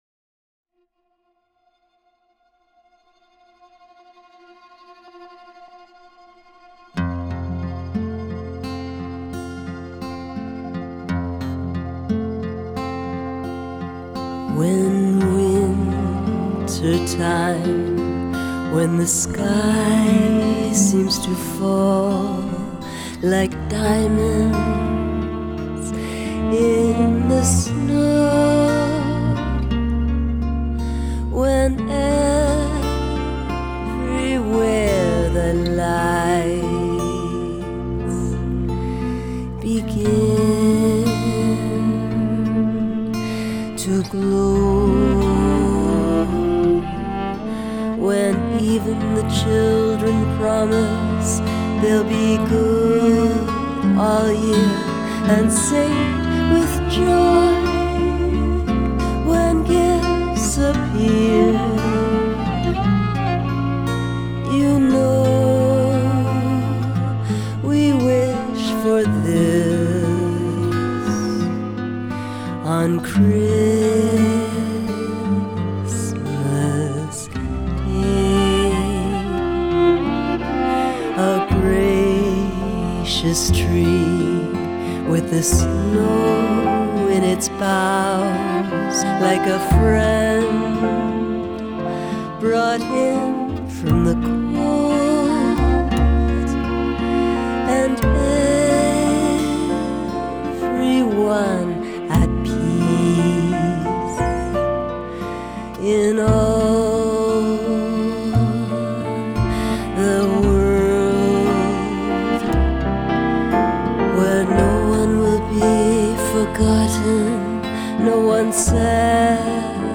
vocal
piano
viola
guitar
bass